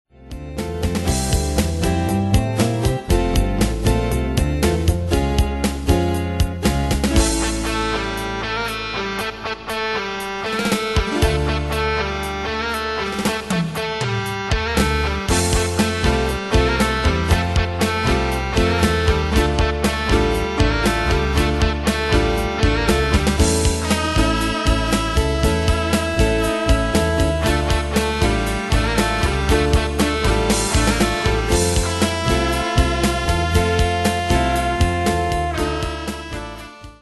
Style: PopAnglo Année/Year: 1994 Tempo: 118 Durée/Time: 3.36
Danse/Dance: Rock Cat Id.
Pro Backing Tracks